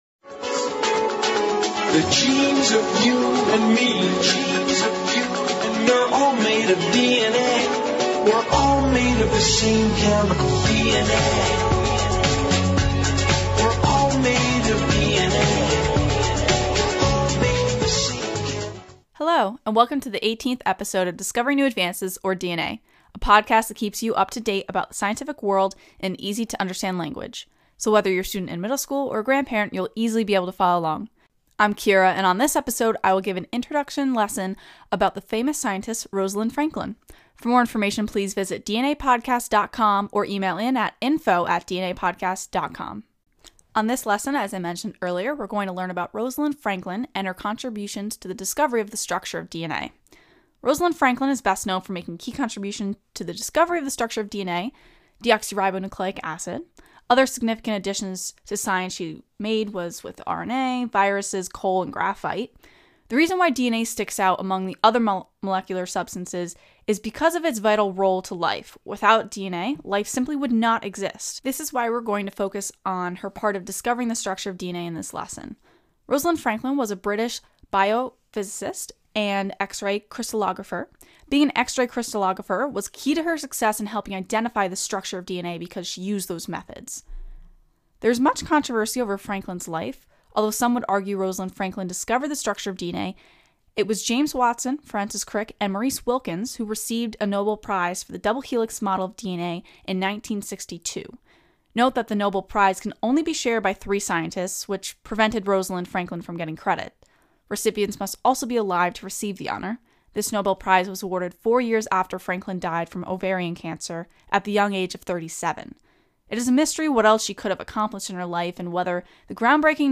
Lesson, Scientist